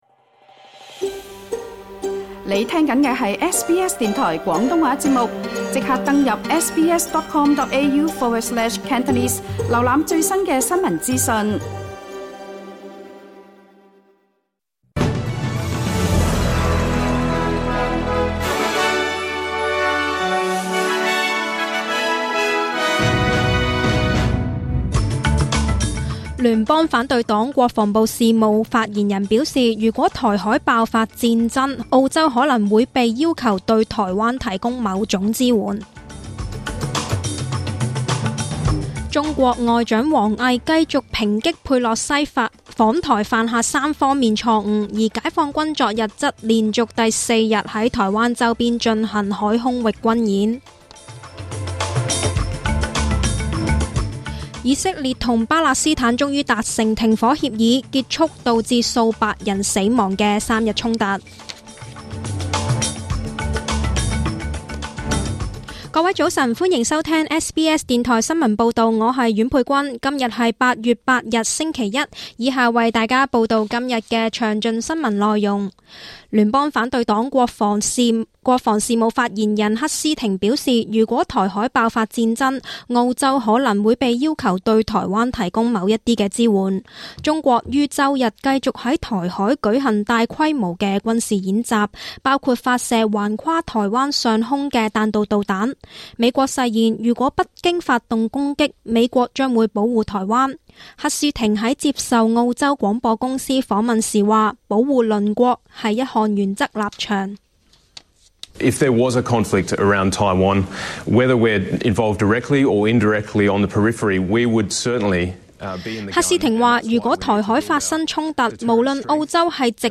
SBS 中文新聞 （8月8日）